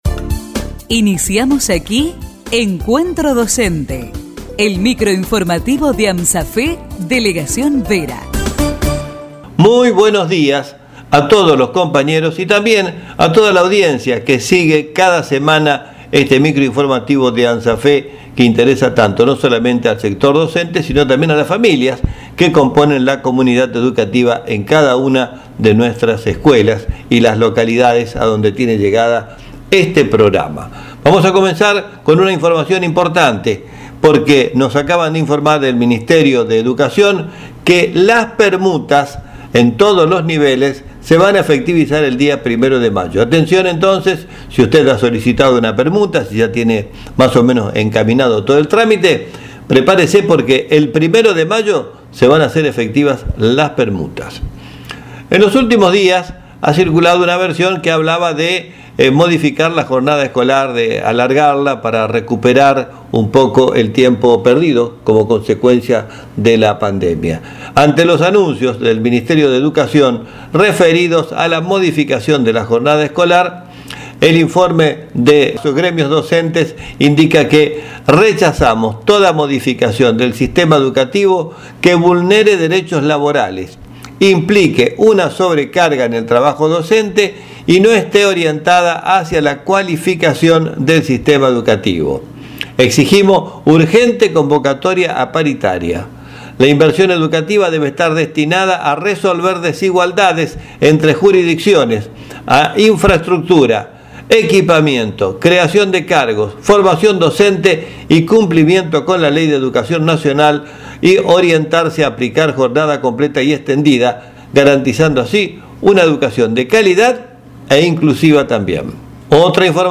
Micro informativo de Amasafe Vera.